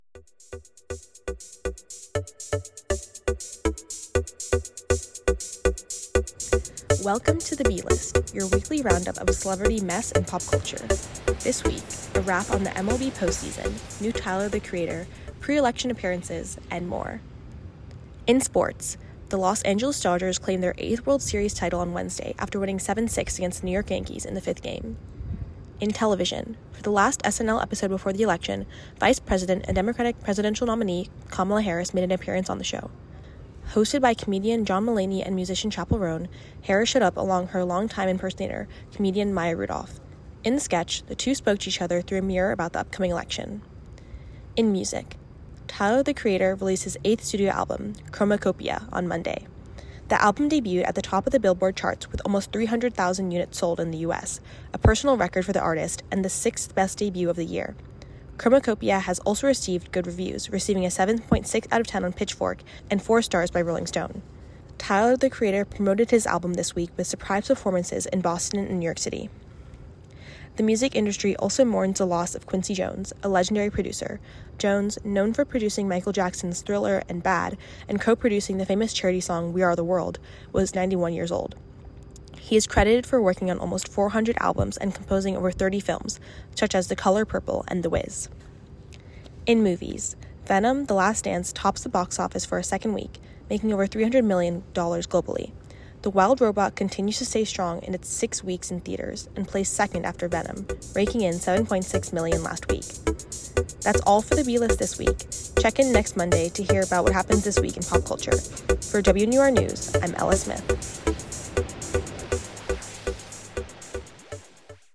Theme music is from MixKit “What What?! Wowow!” by Michael Ramir C.